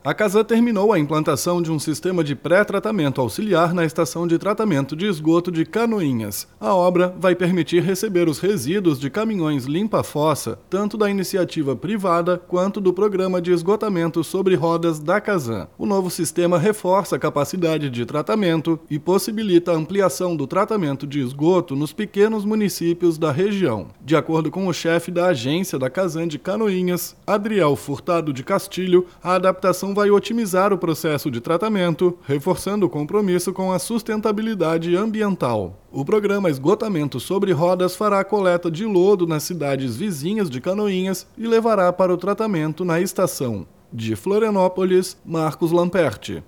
BOLETIM – Casan faz melhorias na ETE Canoinhas para ampliar saneamento na região Norte